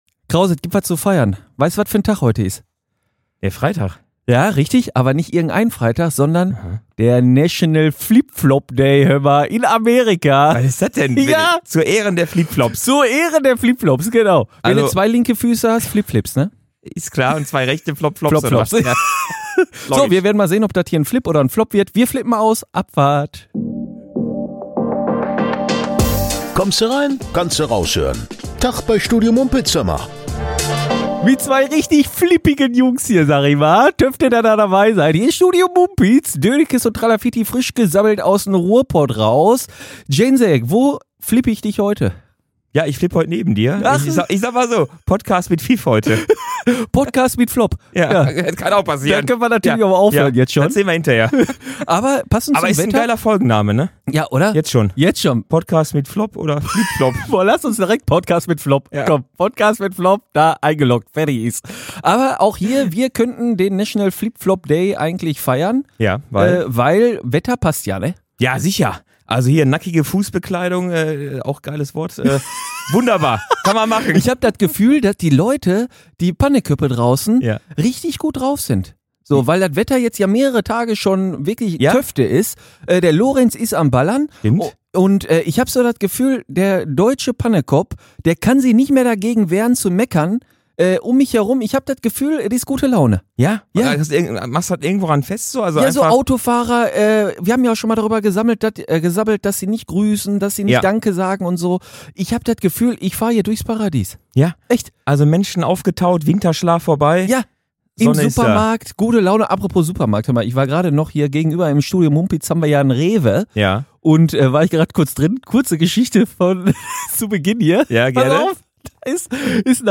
Unter anderem mit Ruhrpott-Detusch zum Thema "Gurke", Betruch in Mülheim bei Sparkassen-Kunden, Städte im Ruhrgebiet inne Bewertung vom Allgemeinen Deutschen Fahrrad Club und nen kaputter Stuhl von Van Gogh im Museum.